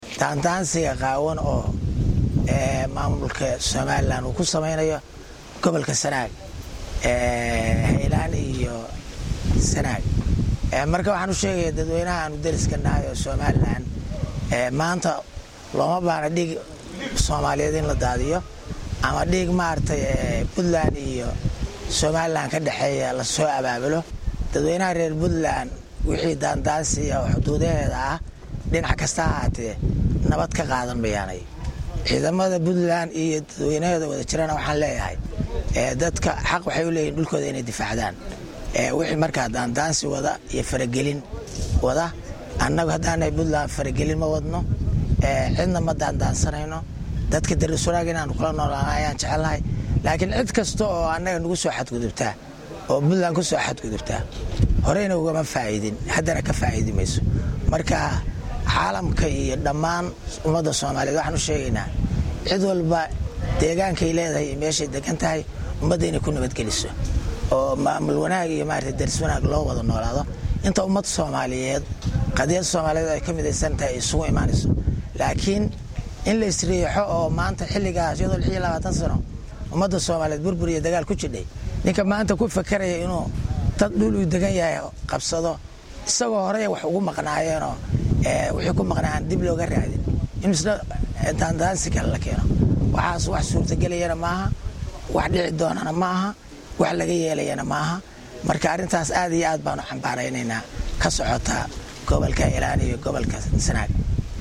Wasiirka Wasaaradda Amniga dawladda Puntland Cabdi Xirsi Cali Qarjab oo maanta 16-July-2016 shir Jaraa’id ku qabtay Aqalka Madaxtooyada dawladda Puntland ee magaalada Gaalkacyo ayaa farriin adag u diray maamulka Somaliland oo maalimihii ugu dambeeyey dhaq-dhaqaaqyo ciidan ka wadey gobollada Sanaag iyo Haylaan.
Dhagayso Wasiirka Amniga